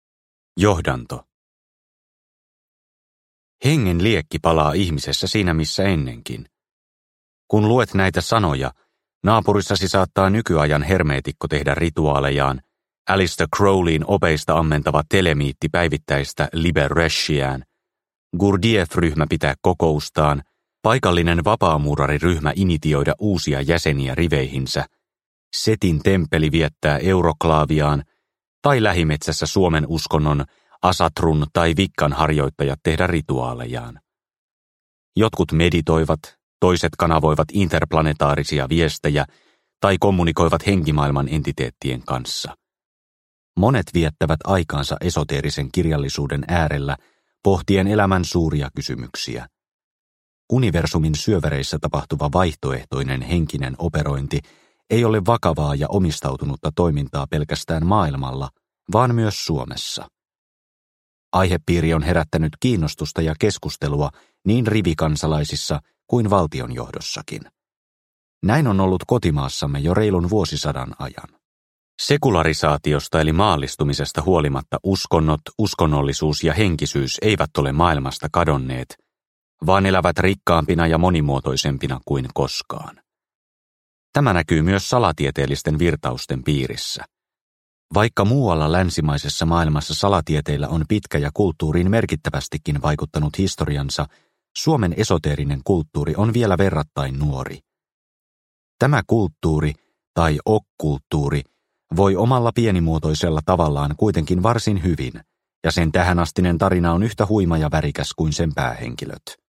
Valonkantajat – Ljudbok – Laddas ner